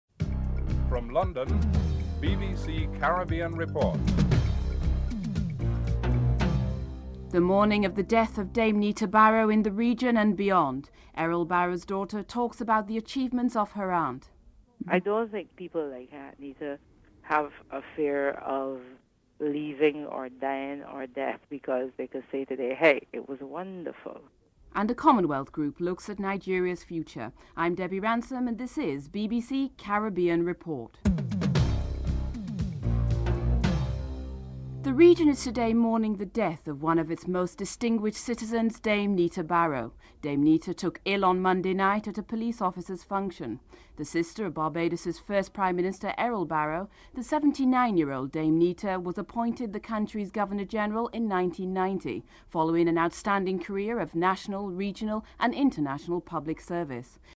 People on the street of Bridgetown give their reactions to Dame Nita's death.
Caribbean Report has Dame Nita Barrow's last public speech given when she distributed gifts to the needy at the local Salvation Army.
3. Dame Nita Barrow's last public speech before her death (08:39-09:28)